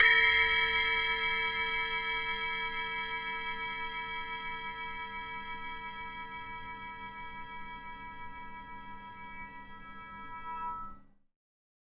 描述：破钹
Tag: 环境 噪音 MSIC